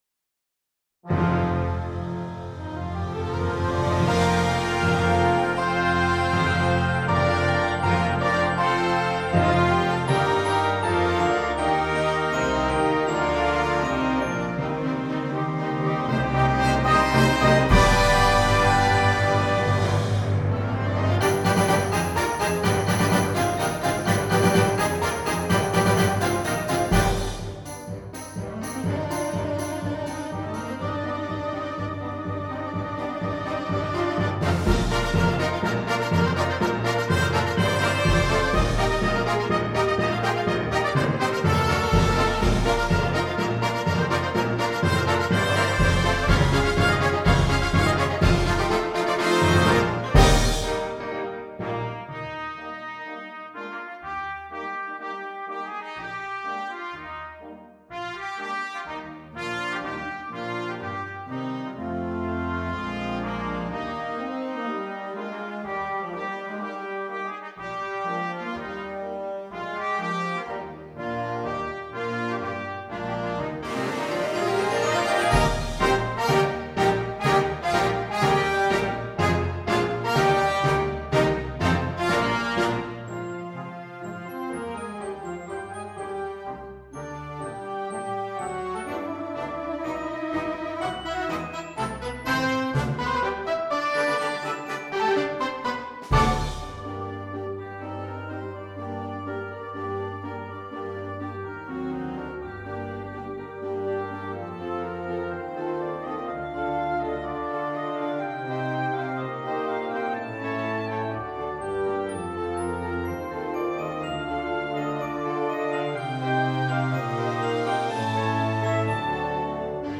Gattung: Für Flexible Besetzung
5:30 Minuten Besetzung: Blasorchester PDF